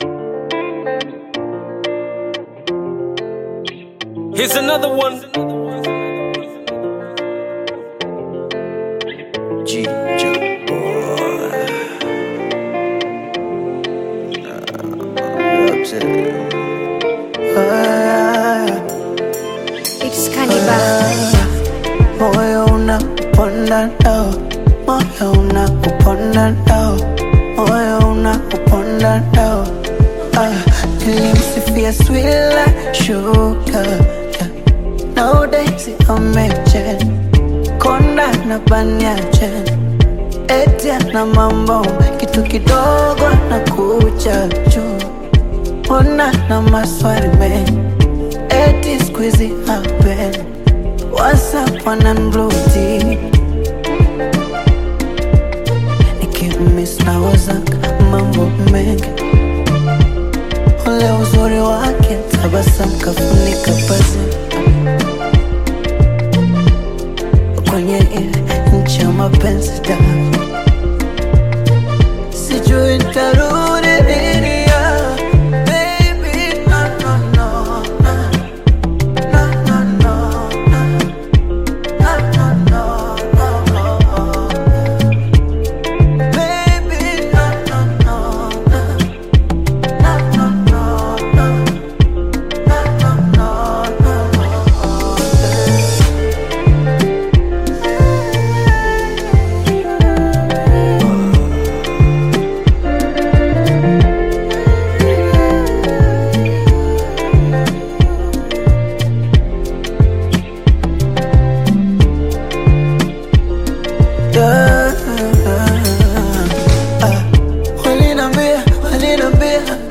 Tanzanian upcoming Bongo Flava artist, singer and songwriter
Bongo Flava You may also like